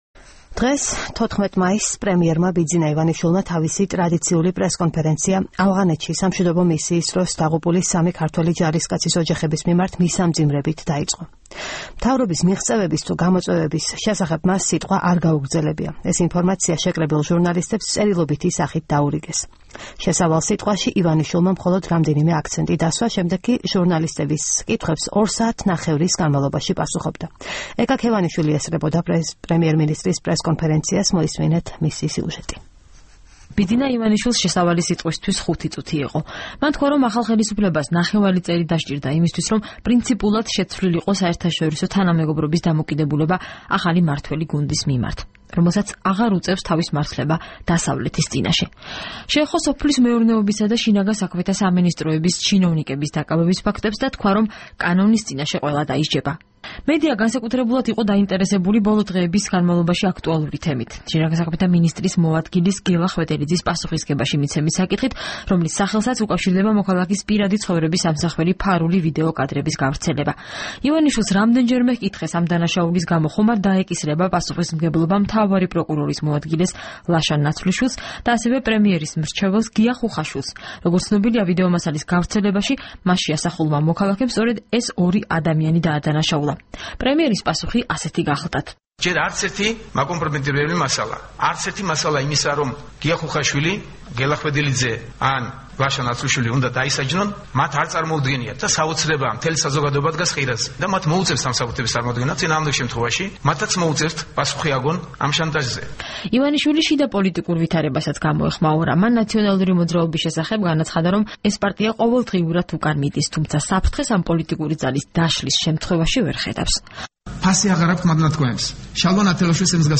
ბიძინა ივანიშვილის პრესკონფერენცია და შენიშვნები მედიას